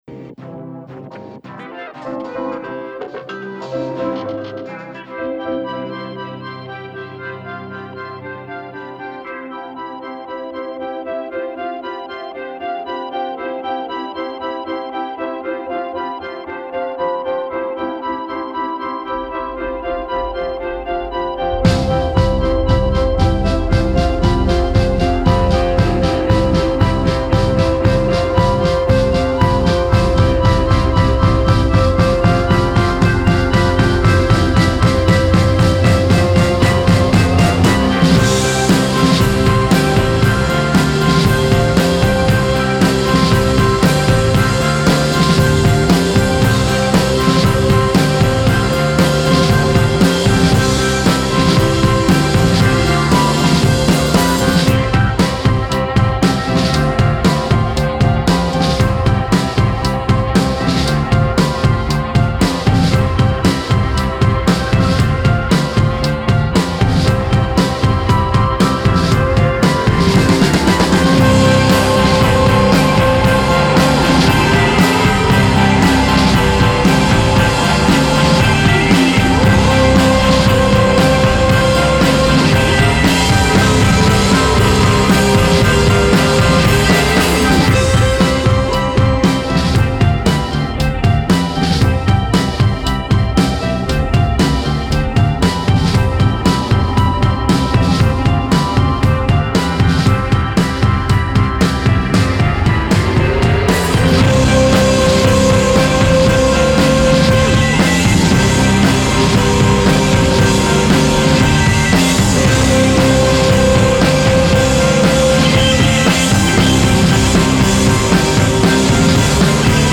Empiezo con una canción instrumental.